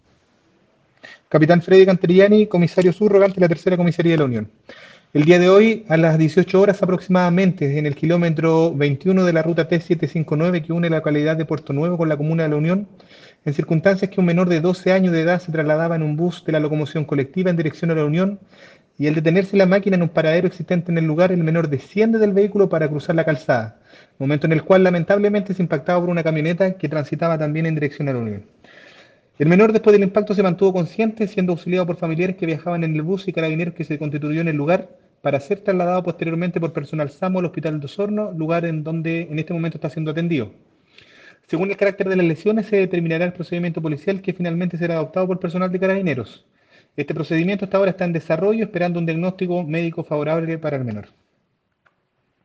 Oficial  de Carabineros ..